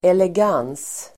Uttal: [eleg'an:s (el. -'angs)]